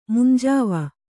♪ munjāva